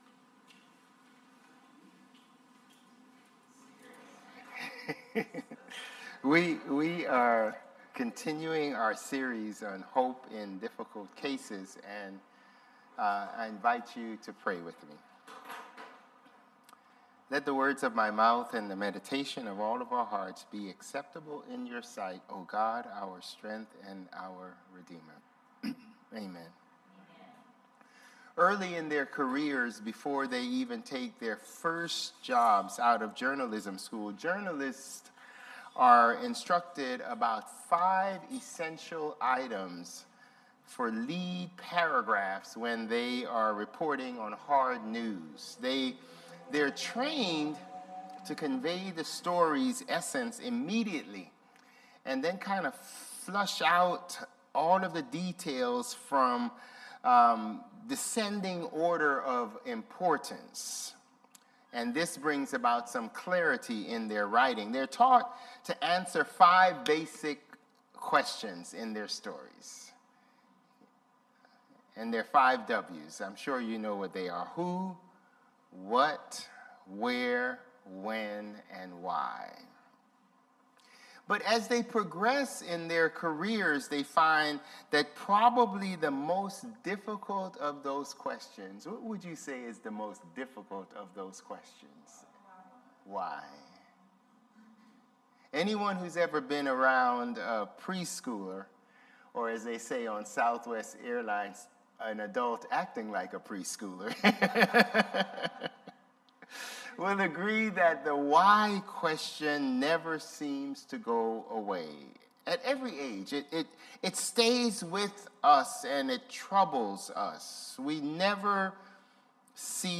Sermons | Bethel Lutheran Church
September 21 Worship